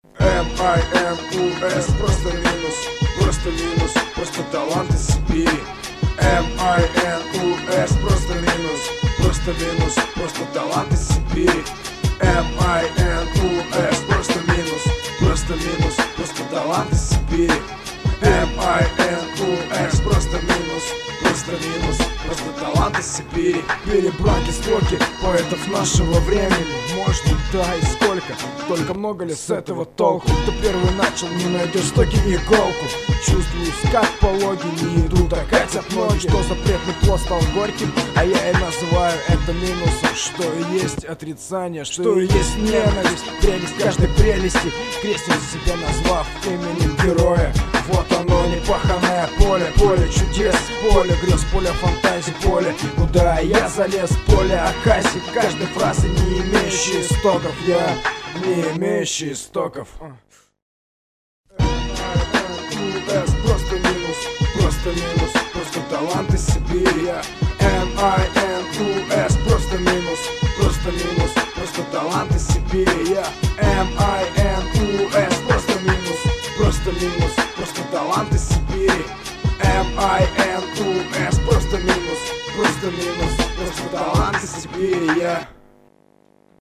mp3,1353k] Рэп